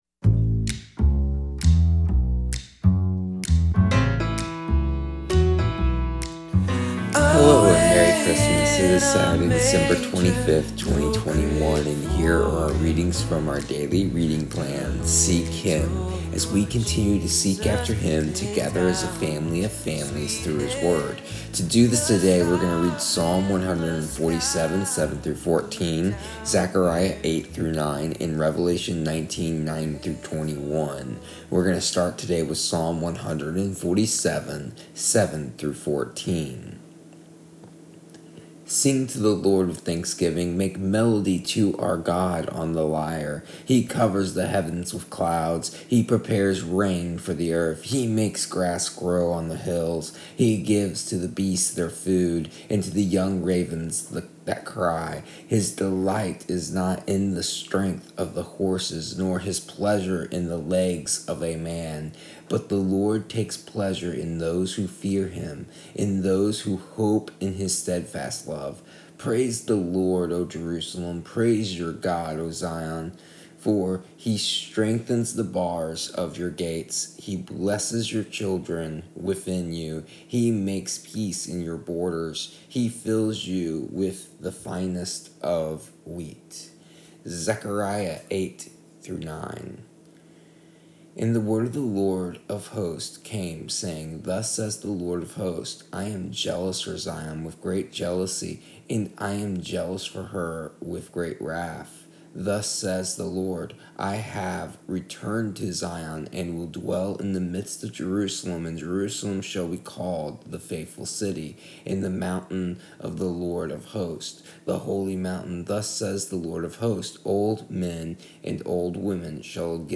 Here is the audio version of our daily readings from our daily reading plan Seek Him for December 25th, 2021.